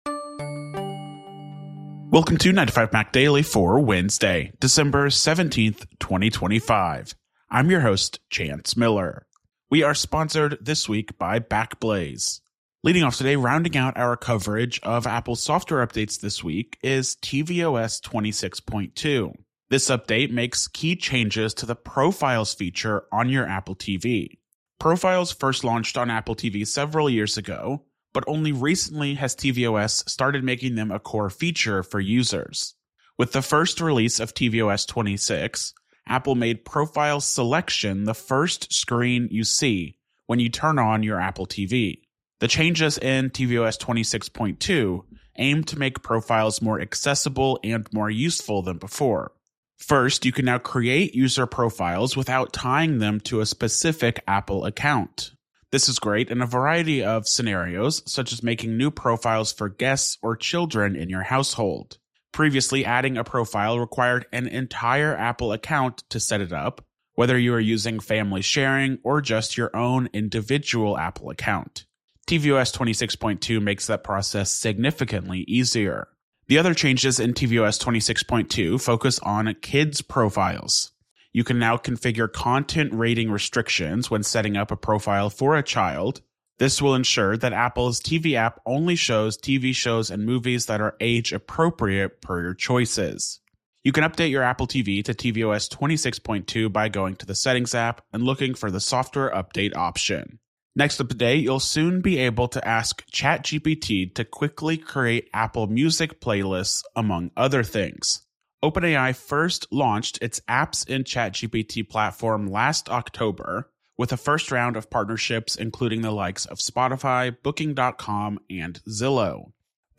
استمع إلى ملخص لأهم أخبار اليوم من 9to5Mac. 9to5Mac يوميا متاح على تطبيق iTunes وApple Podcasts, غرزة, TuneIn, جوجل بلاي، أو من خلال موقعنا تغذية RSS مخصصة لـ Overcast ومشغلات البودكاست الأخرى.